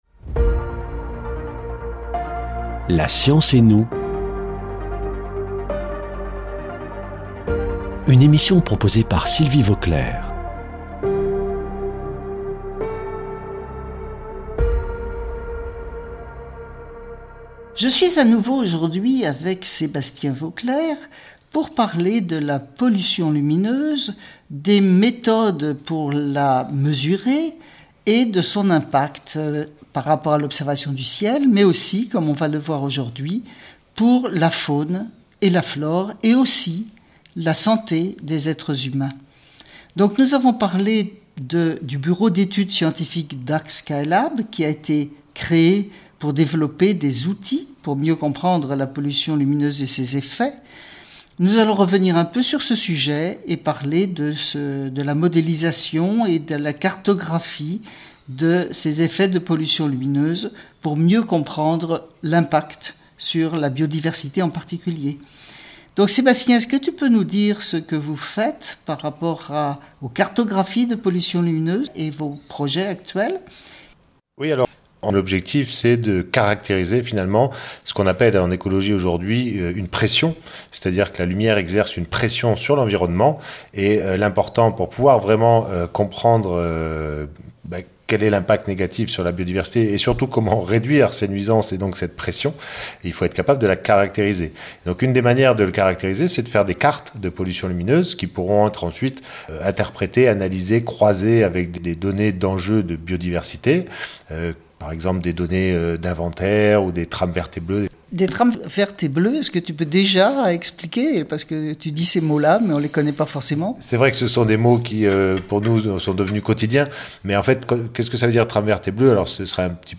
[ Rediffusion ]
spécialiste de la pollution lumineuse